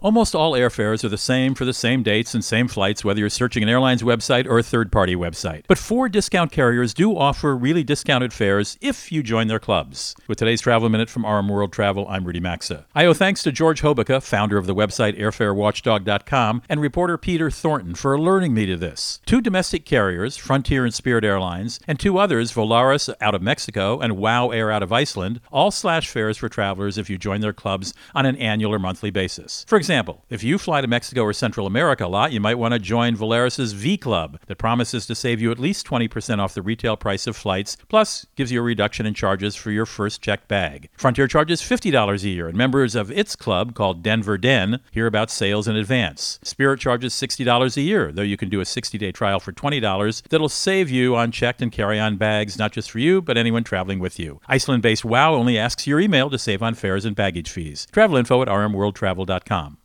America's #1 Travel Radio Show
Host Rudy Maxa | Finding Secret Airfare Deals at Discount Carriers